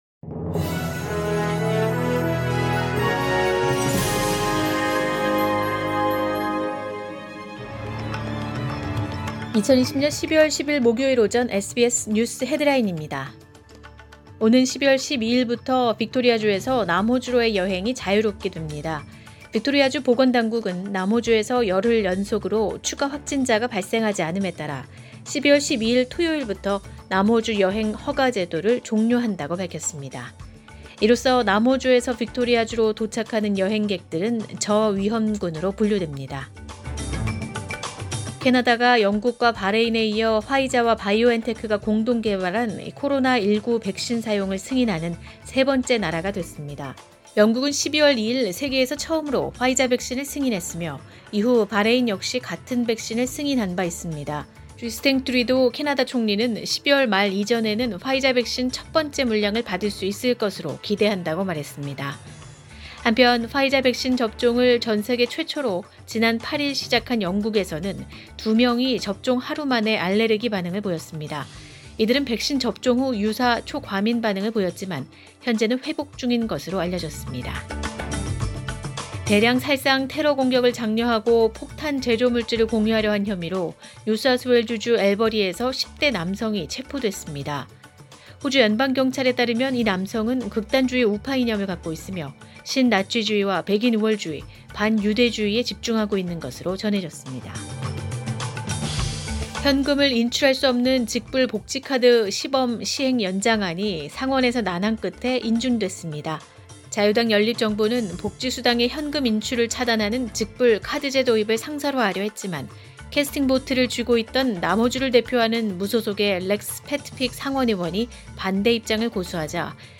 2020년 12월 10일 목요일 오전의 SBS 뉴스 헤드라인입니다.